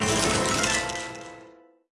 Media:RA_Kitsune_Witch_Dep_002.wav 部署音效 dep 局内选择该超级单位的音效
RA_Kitsune_Witch_Dep_002.wav